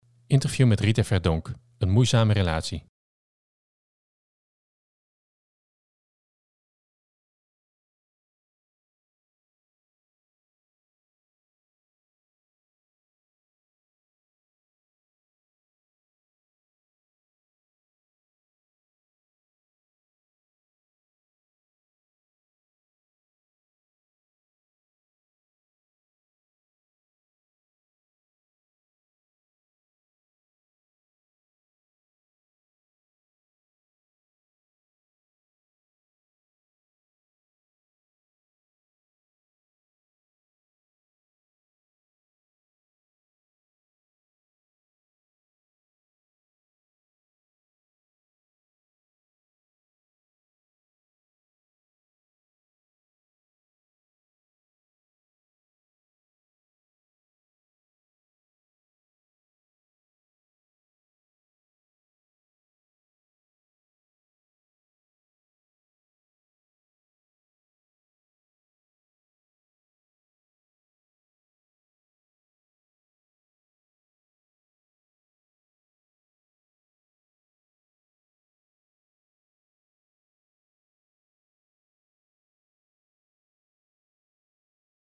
Interview met Rita Verdonk